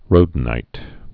(rōdn-īt)